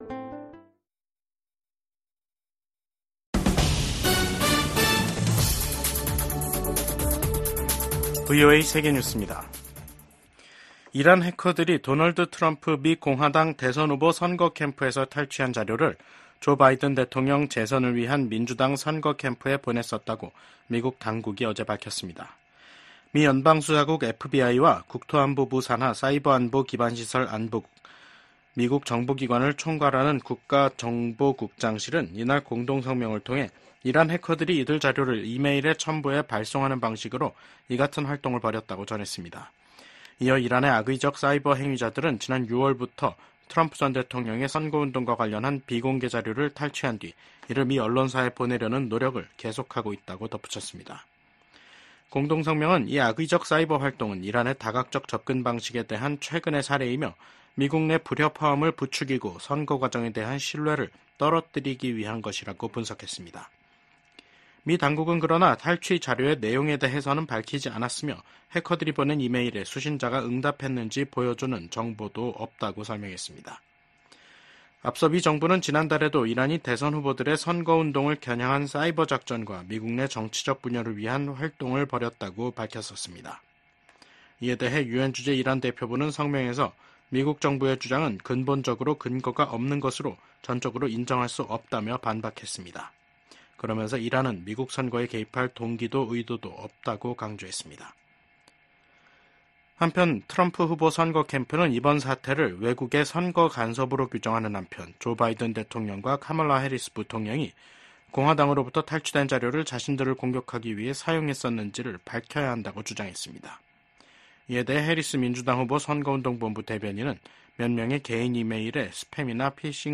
VOA 한국어 간판 뉴스 프로그램 '뉴스 투데이', 2024년 9월 19일 2부 방송입니다. 북한은 고중량 고위력의 재래식 탄두를 장착한 신형 단거리 탄도미사일 시험발사에 성공했다고 밝혔습니다. 미국 국무부 부장관이 북한과 러시아의 협력이 북한으로 하여금 더 도발적인 행위를 하도록 부추길 우려가 있다고 말했습니다. 유럽연합과 영국 프랑스, 독일 등 유럽 주요국이 엿새 만에 재개된 북한의 미사일 발사를 강력하게 규탄했습니다.